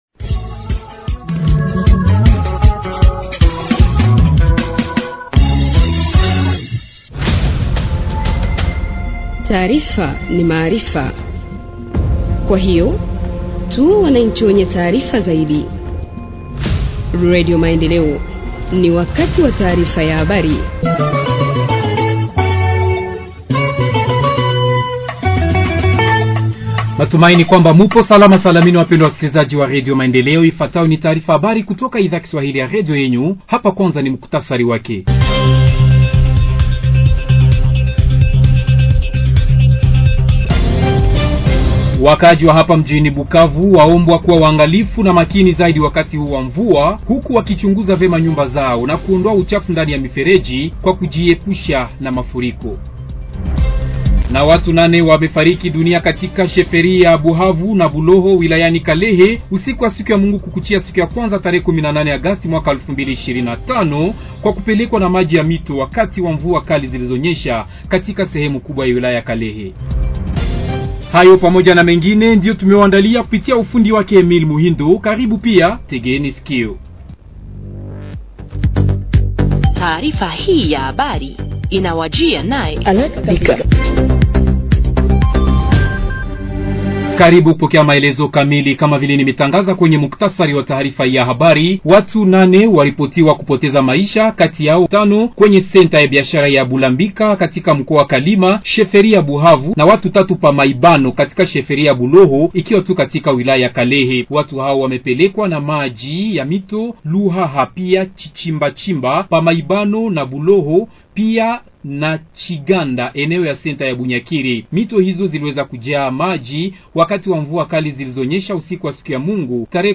Journal en Swahili du 19 Aout 2025 – Radio Maendeleo